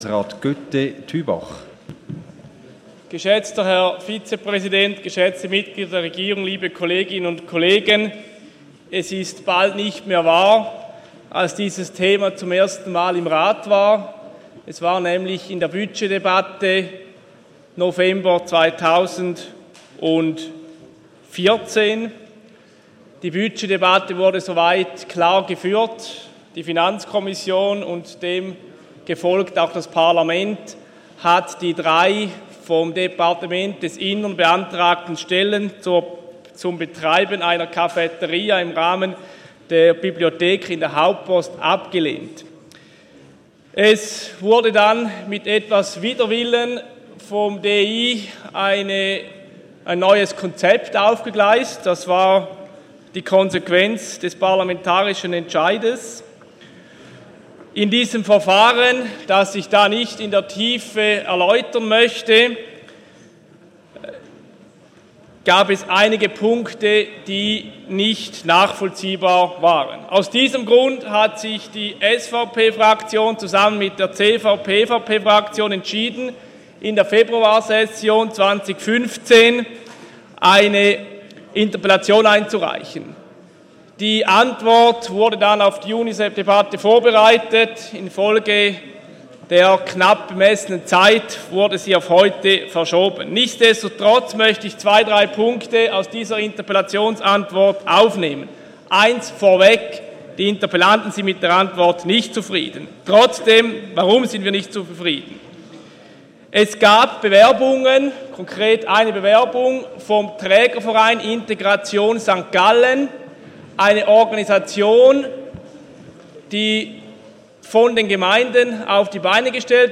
14.9.2015Wortmeldung
Session des Kantonsrates vom 14. bis 16. September 2015